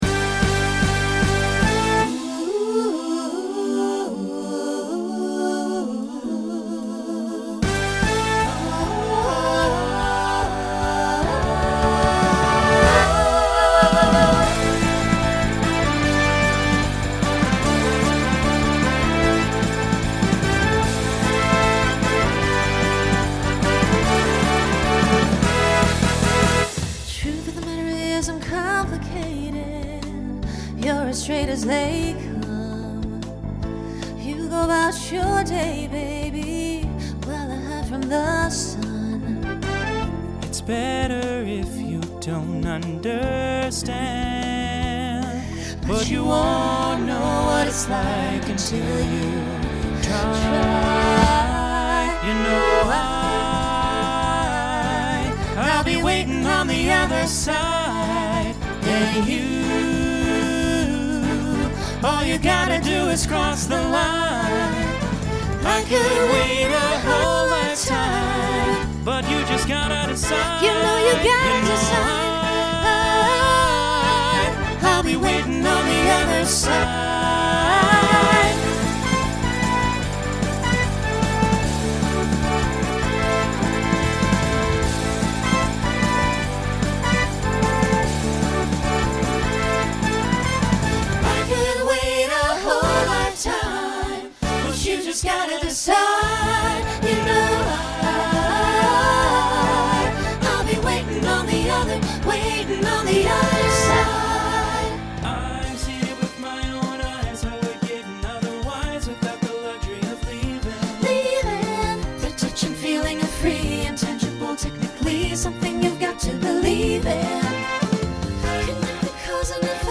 Starts with treble/bass duet, the rest is SATB.
Genre Rock